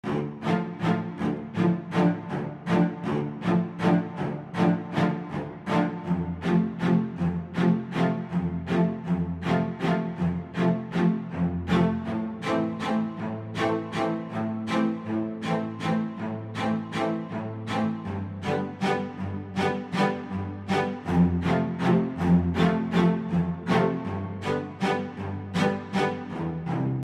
描述：一些你会喜欢的小提琴管弦乐。
Tag: 160 bpm RnB Loops Violin Loops 2.27 MB wav Key : Unknown